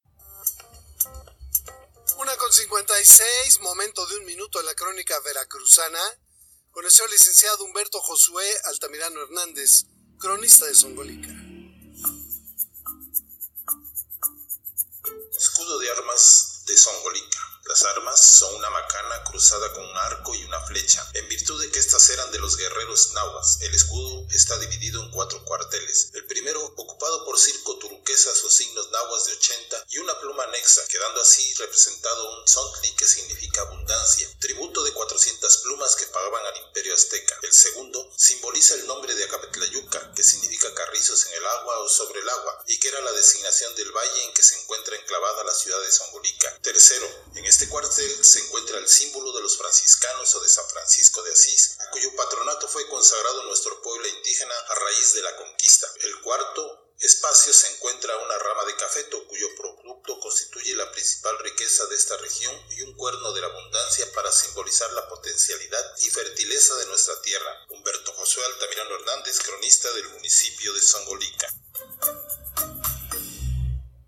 Aquí les compartimos las grabaciones sacadas al aire de la sección del noticiero referido.